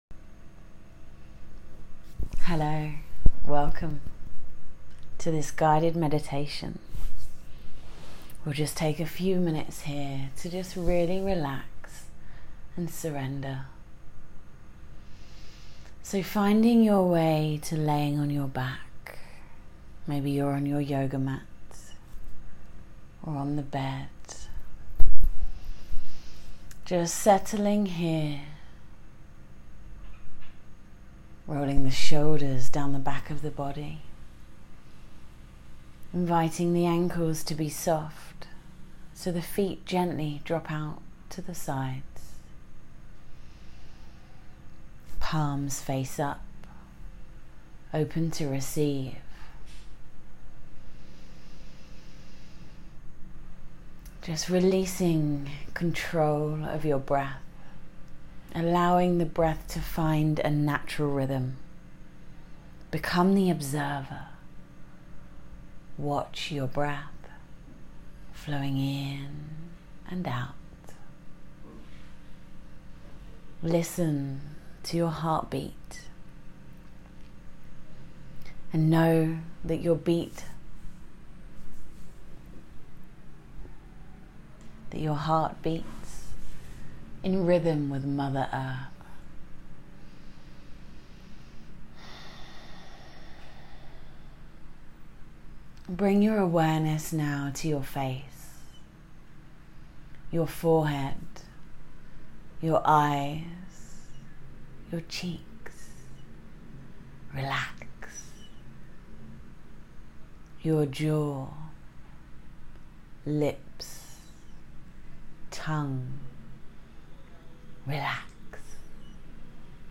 Free Guided Meditation
Meditation-for-solar-plexus-purpose.m4a